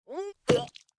Npc Catspit Sound Effect
npc-catspit-1.mp3